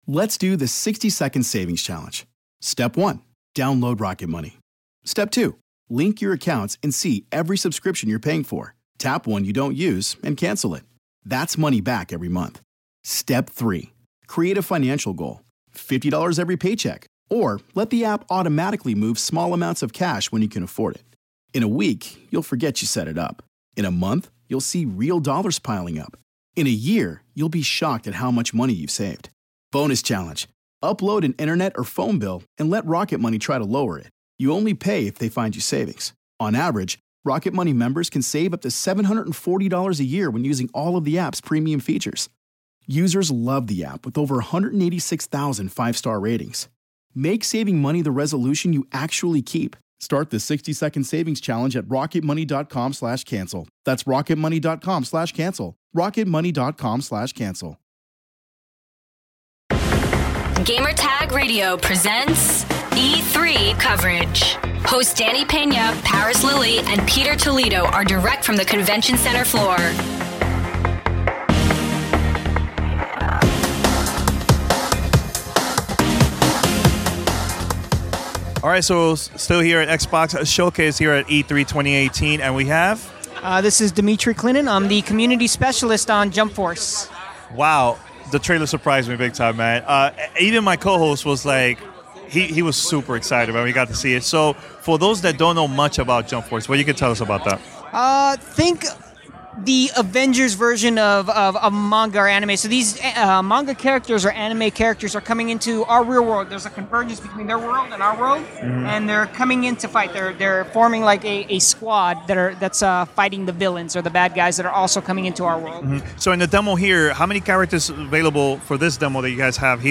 Jump Force Interview.